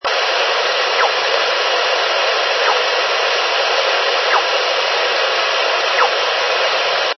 Ils ont été effectués à l'aide d'un camescope placé près du haut-parleur d'un MVT-7100.
Enregistrement 3 : SSB. Microbalise seule. La porteuse n'est pas modulée, sa fréquence varie légèrement au moment du passage en émission ce qui provoque le piaulement "piou...piou..." qui rappelle le cri d'un (petit) oiseau.